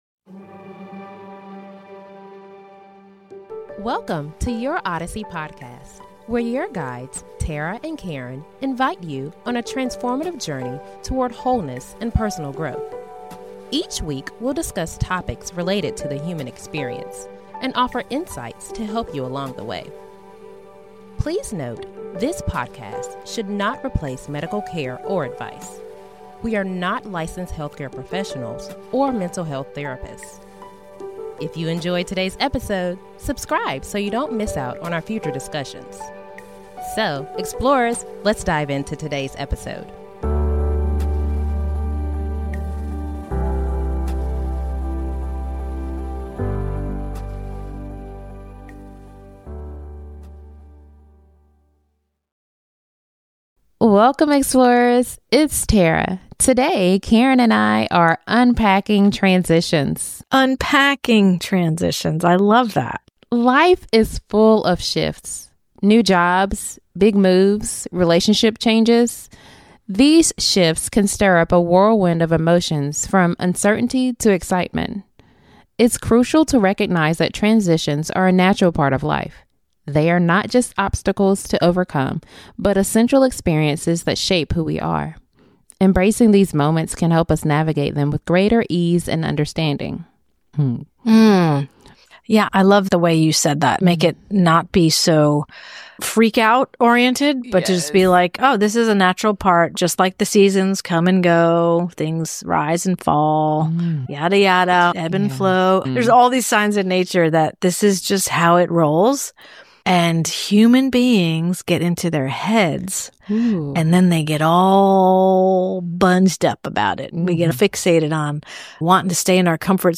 Our hosts share heartfelt personal stories and offer practical advice for navigating these shifts, reminding us that each transition is not just an obstacle but an opportunity for profound growth. With a blend of lighthearted banter and profound insights, this episode offers the inspiration you need to confidently embrace life's twists and turns.